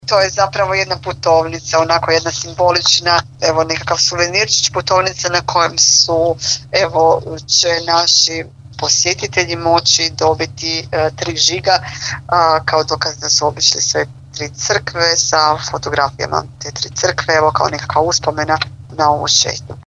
Koji je to suvenir, saznajemo od zamjenice župana Tanje Novotni Golubić: